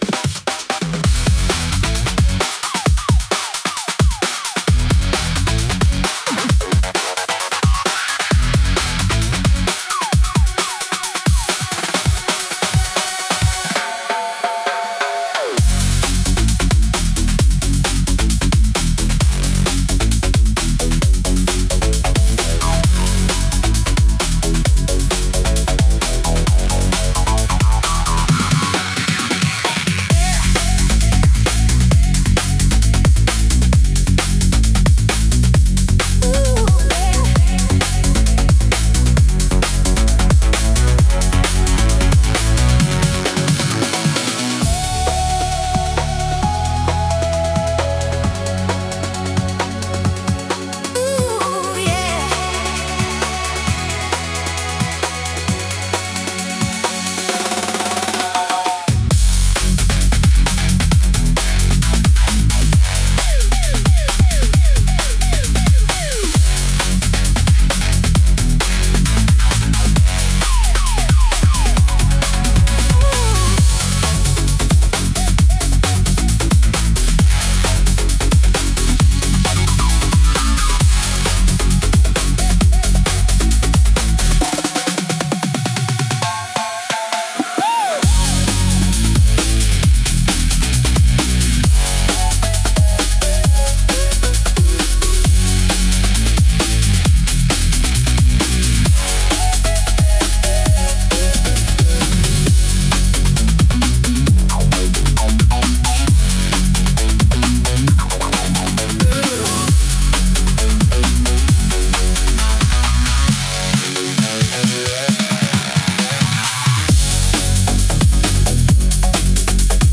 イメージ：130BPM,ブレイクビート・エレクトロニック・ロック,ドラムンベース
インストゥルメンタル（instrumental）